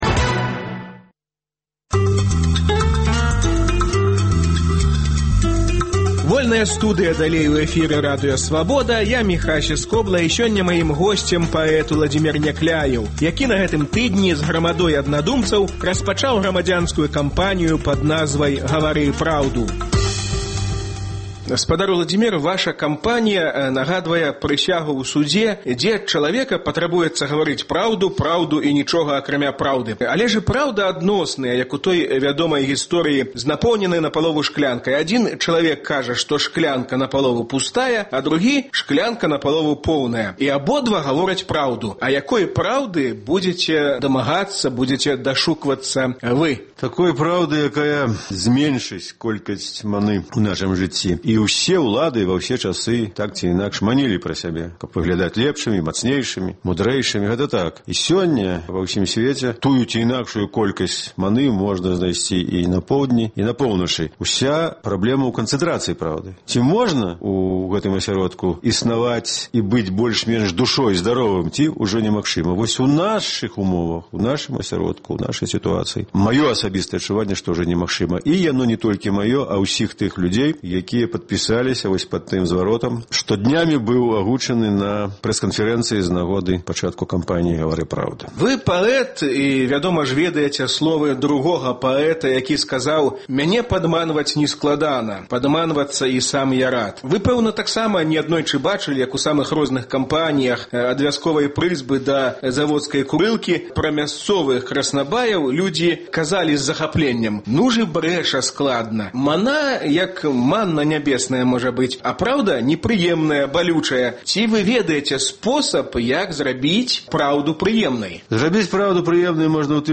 Гутарка з Уладзімерам Някляевым наконт грамадзянскай кампаніі “Гавары праўду!” (паўтор ад 28 лютага)